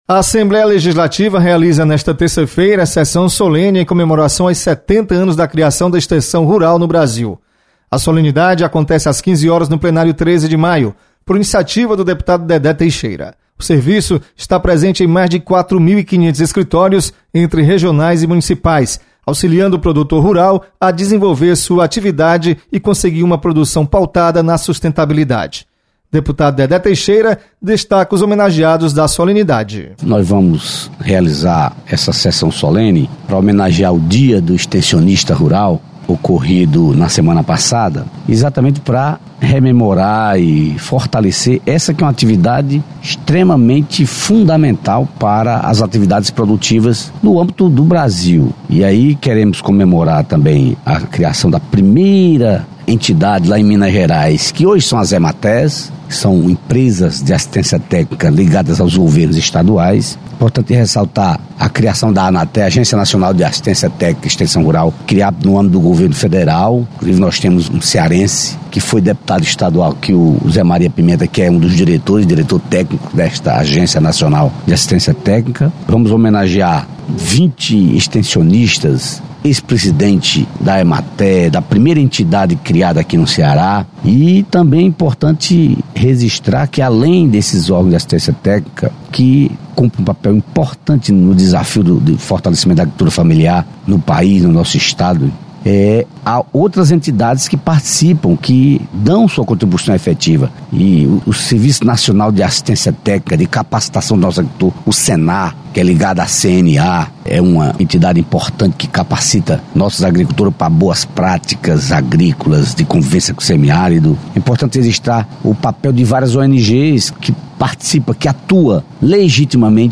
Solenidade